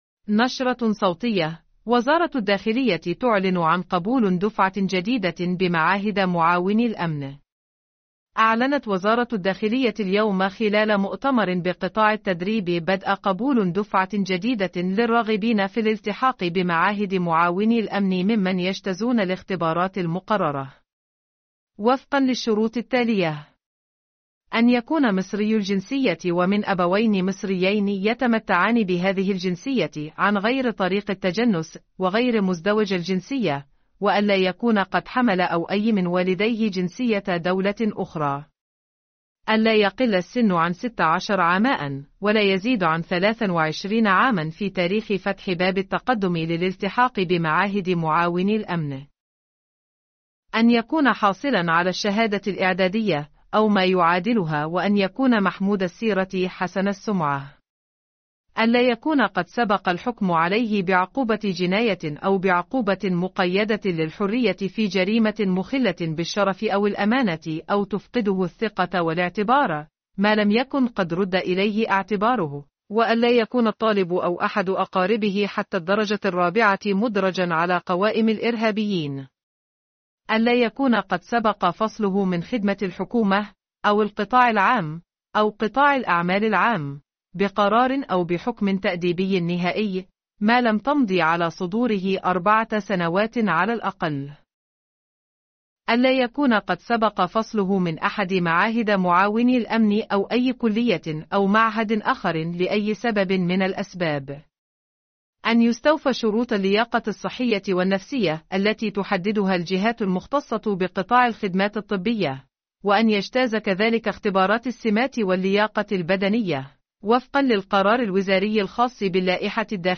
نشرة صوتية: وزارة الداخلية تُعلن عن قبول دفعة جديدة بمعاهد معاونى الأمن